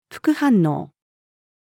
副反応-female.mp3